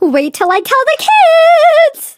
flea_lead_vo_01.ogg